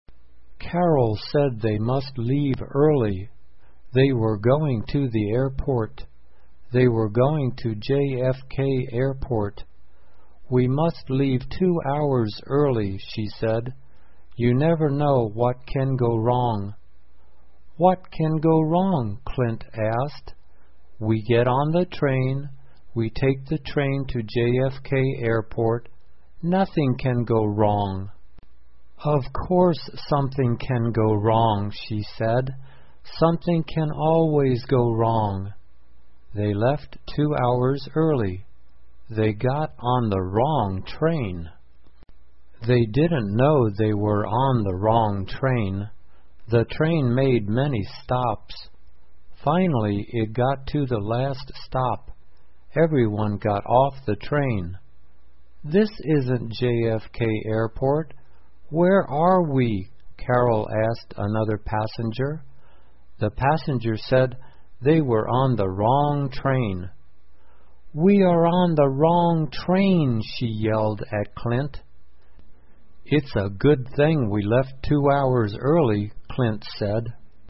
慢速英语短文听力 上错车 听力文件下载—在线英语听力室